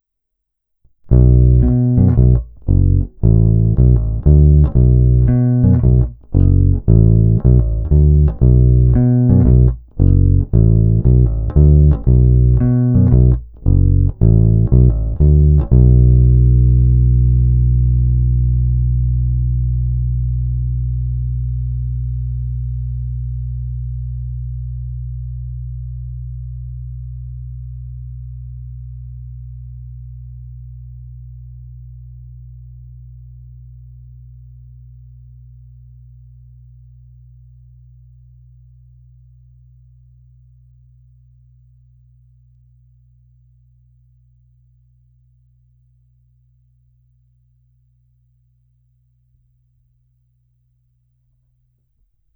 Zvuk je barevný, variabilní, s jinými nástroji jsem si vždycky našel "svůj zvuk", který bych beze změny používal pořád, tady se mi líbí všechny polohy, a to i samotný kobylkový snímač.
Není-li uvedeno jinak, následující nahrávky jsou provedeny rovnou do zvukové karty, jen normalizovány, jinak ponechány bez úprav.
Tónová clona vždy plně otevřená.